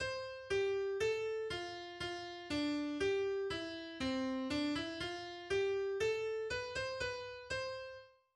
Mündlich überlieferter 2-stimmiger Kanon aus dem 20.